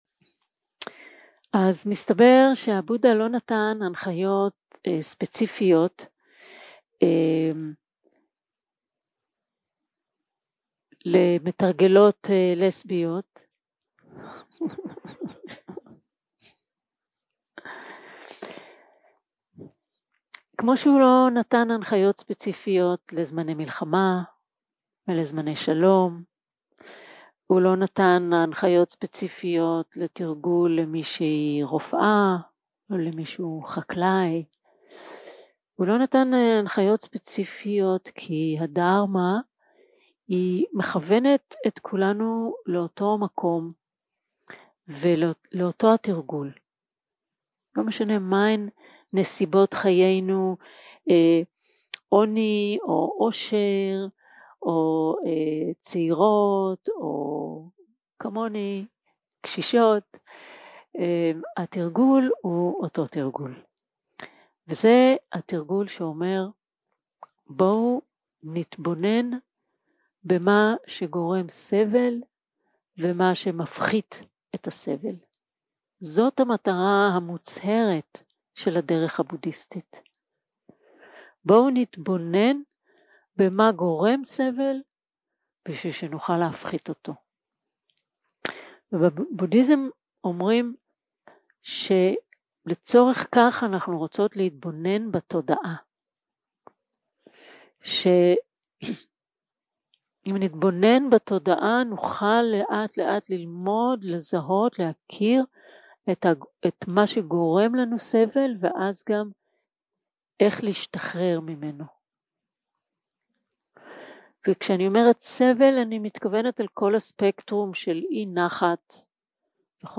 יום 1 - הקלטה 1 - ערב - שיחת פתיחה
Dharma type: Opening talk